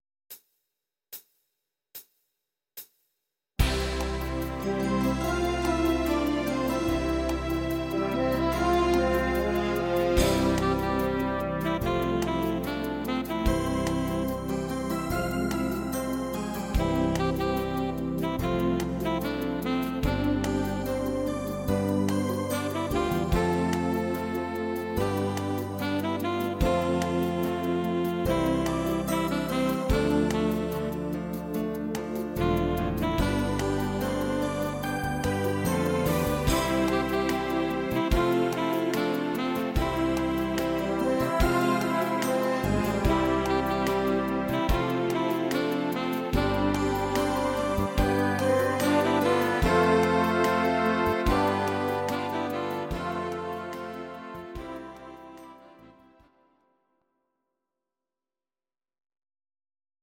Audio Recordings based on Midi-files
German, Traditional/Folk, Volkstï¿½mlich